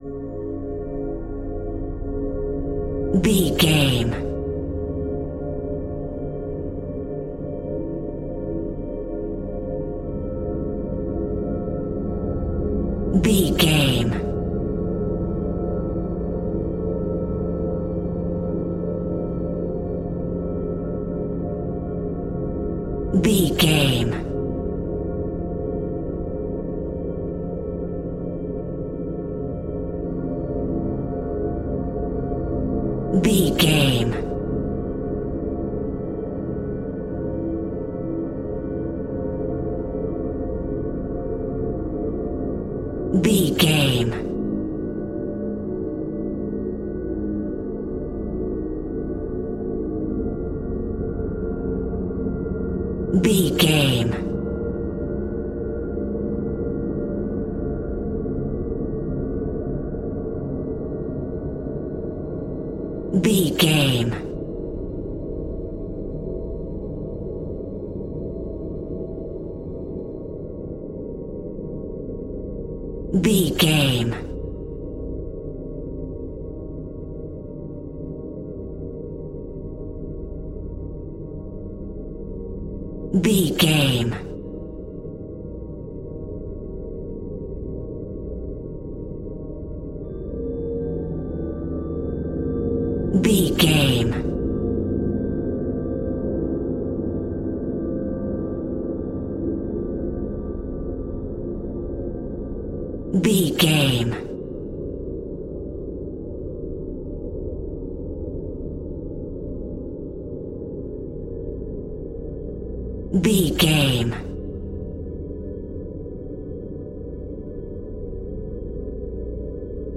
Atonal
scary
ominous
haunting
eerie
synthesizer
ambience
pads
eletronic